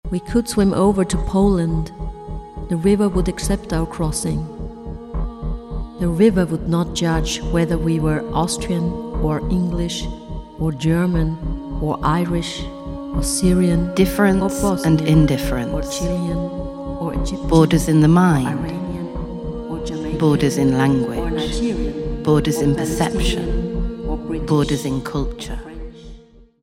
sound & editing
writing & voice